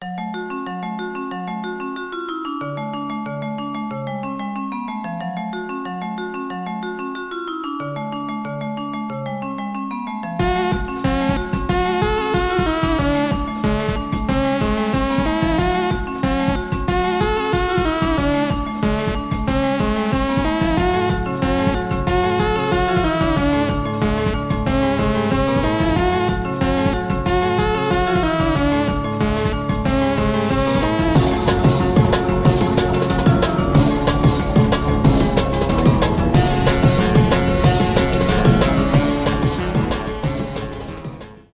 нарастающие